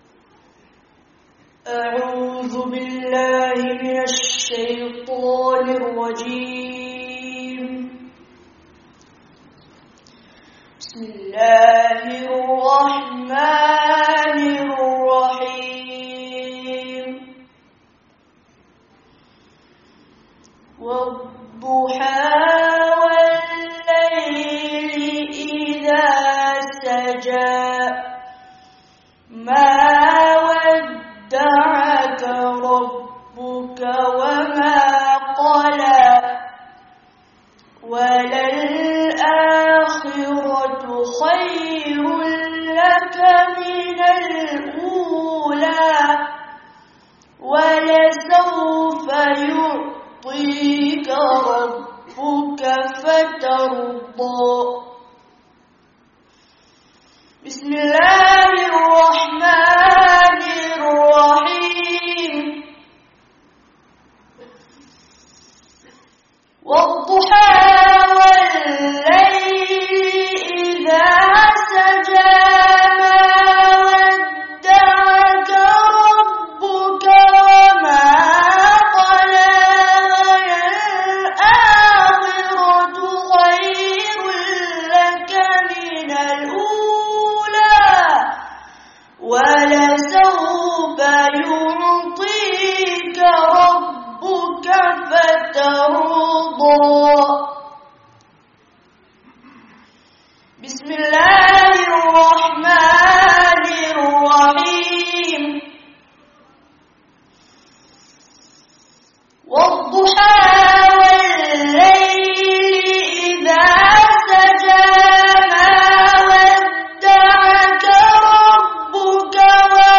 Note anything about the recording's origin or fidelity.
Remain a Tālibul-'Ilm Till Your Last Breath [Annual Jalsah] (Madrasah Riyadul Qur'an, Leicester 09/08/19)